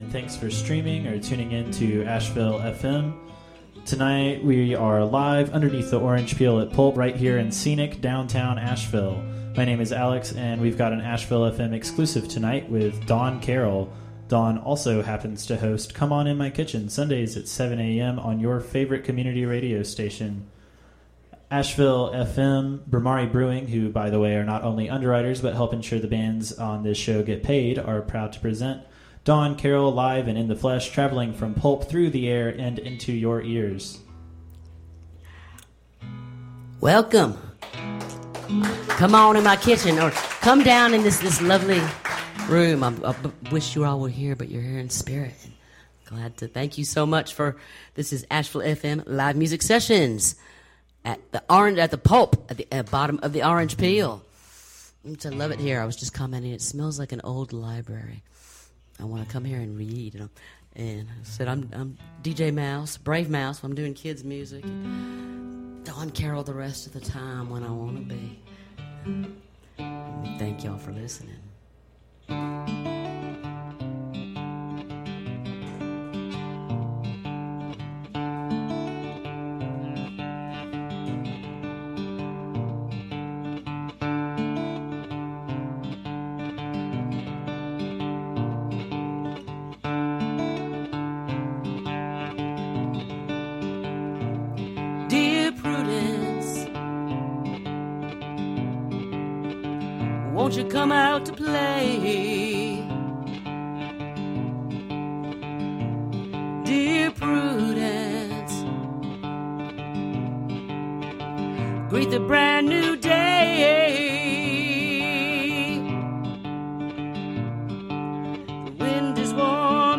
Live from The Pulp
Recorded during safe harbor – Explicit language warning